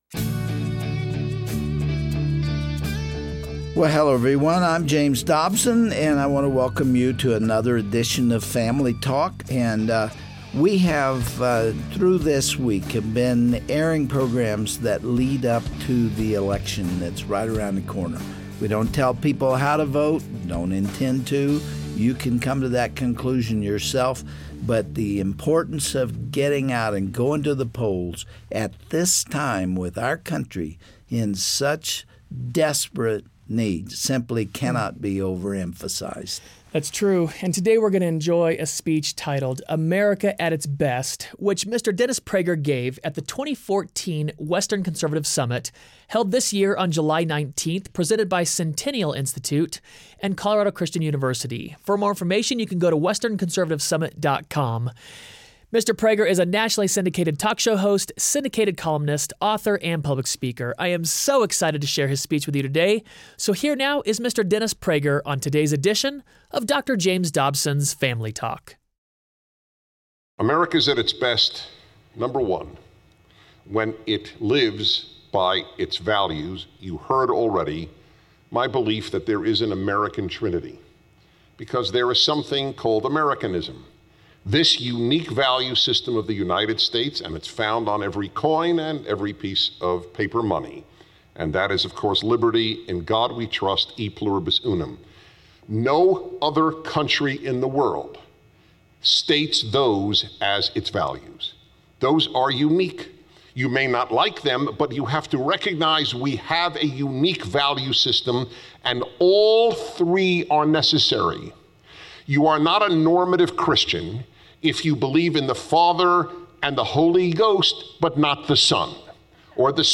What does it look like when America is at its best? On the next edition of Family Talk, Dennis Prager prepares us for Election Day with a speech about the intrinsic qualities that make America a great country.